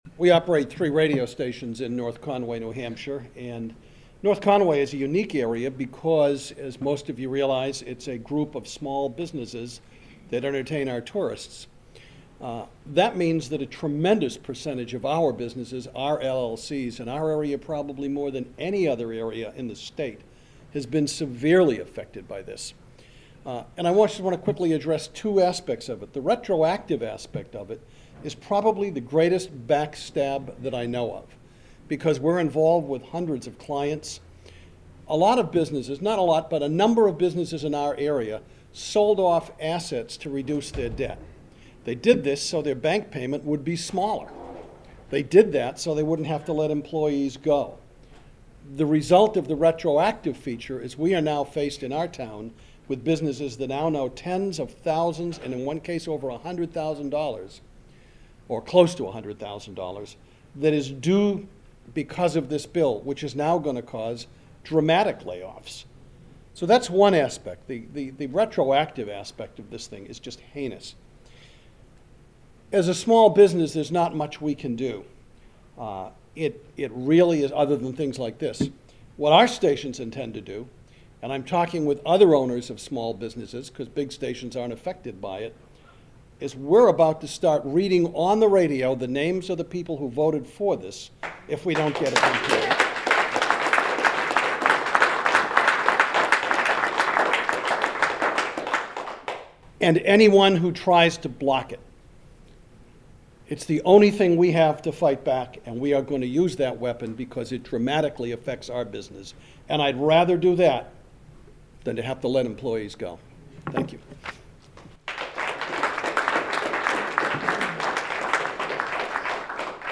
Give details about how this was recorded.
The following audio cuts are soundbytes from the 6/8/10 LLC Tax press conference at the State House and aired on the show: